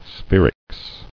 [spher·ics]